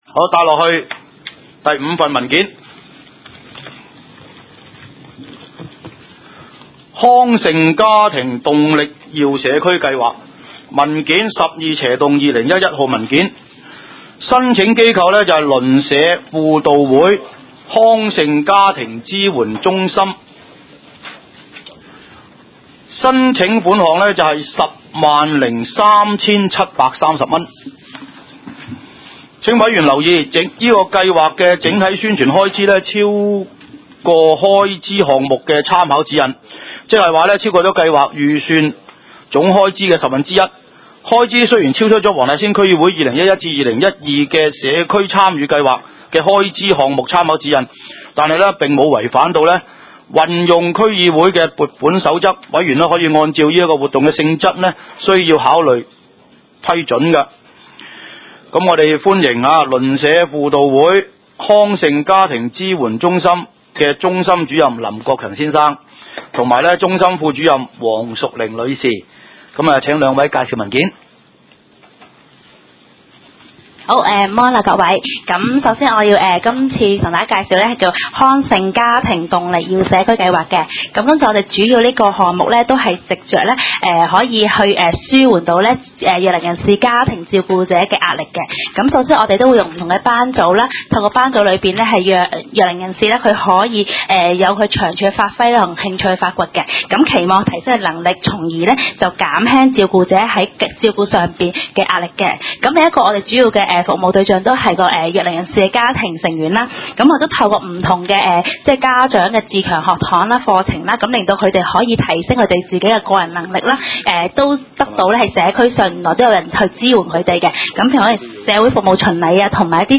第二十一次會議議程